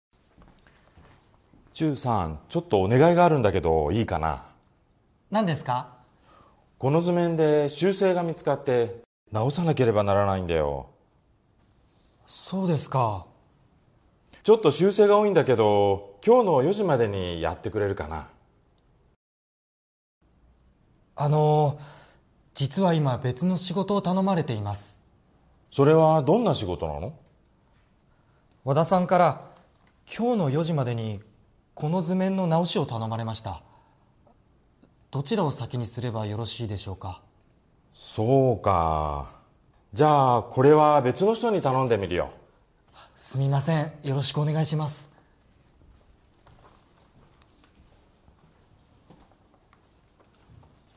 Role-play Setup
Grammar Note: The falling intonation here indicates that the speaker is considering the situation.